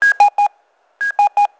Севшая батарейка.
low_battery.wav